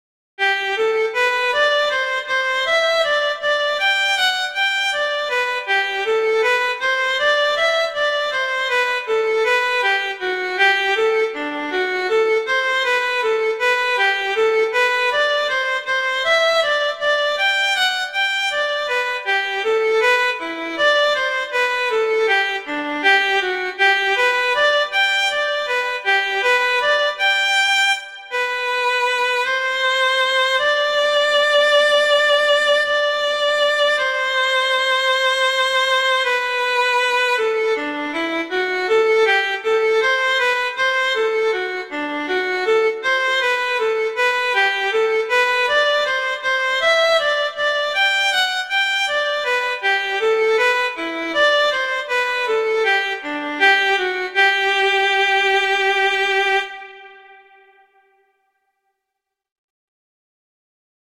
arrangements for violin solo
classical, children